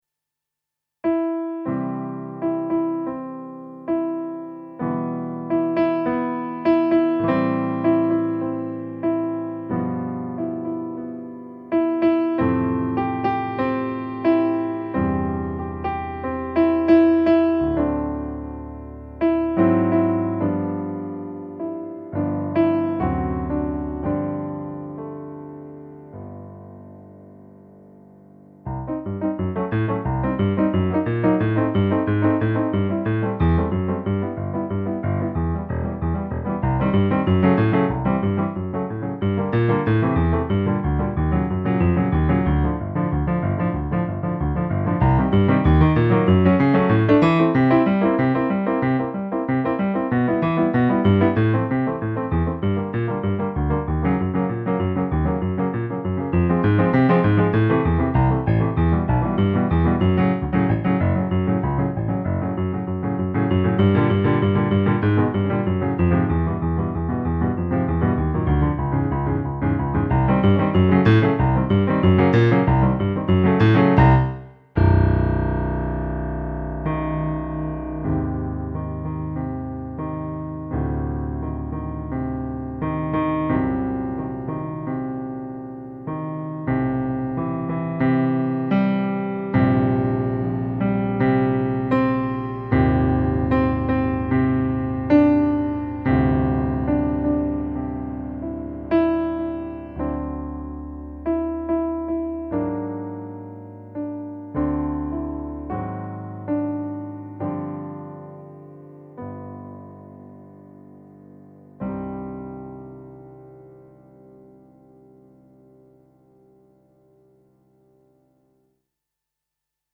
En ce qui concerne la musique, cette petite impro est à chanter bien entendu sur les paroles de "Lundi, des patates..." que je vous redonne dans leur intégralité :